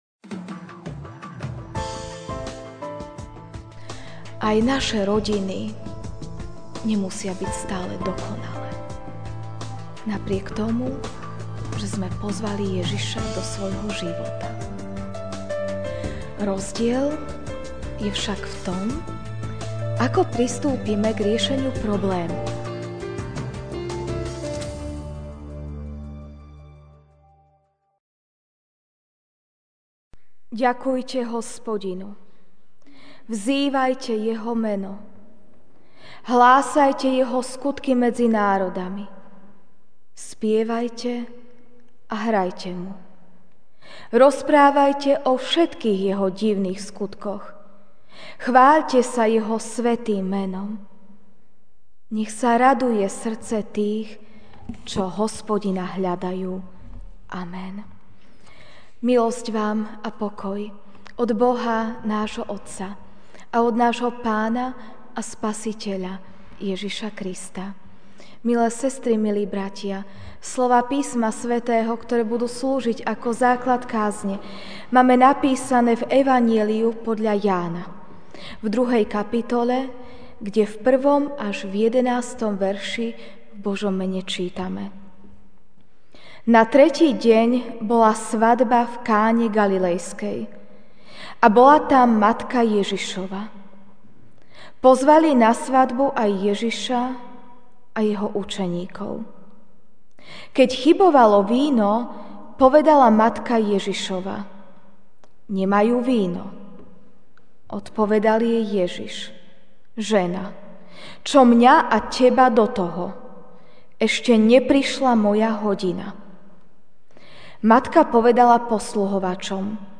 Večerná kázeň: Ježišova prítomnosť posväcuje domácnosť (Ján 2, 1-11) Na tretí deň bola svadba v Káne Galilejskej a bola tam matka Ježišova.